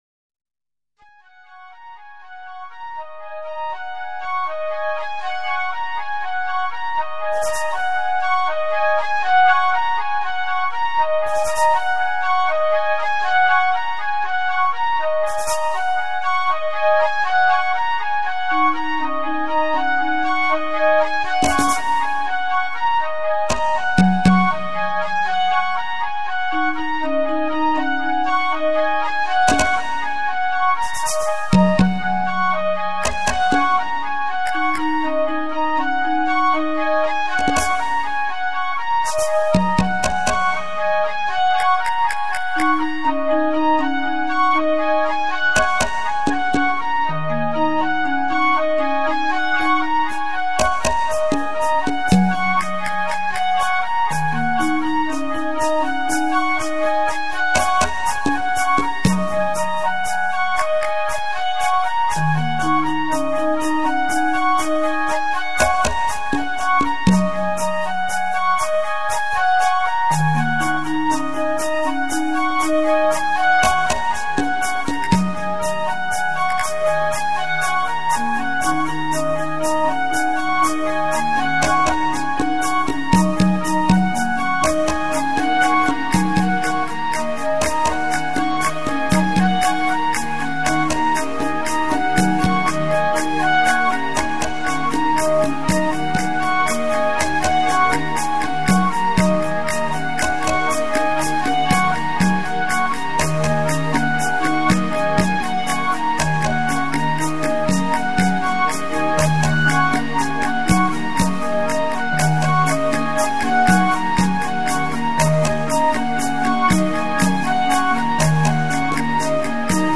Musiche di scena dall'opera teatrale
Sonorizzazioni dal vivo